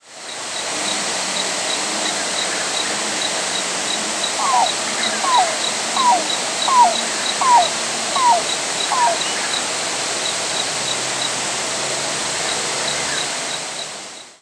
presumed Yellow-billed Cuckoo nocturnal flight calls
Gurgle series.